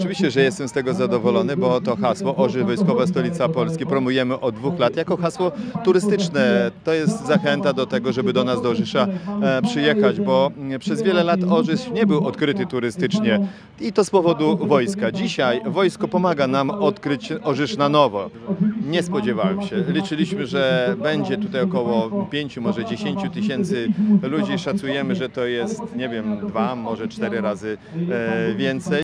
Dumy z tego, że Orzysz nazywany jest wojskową stolicą Polski nie kryje burmistrz miasta, Zbigniew Włodkowski.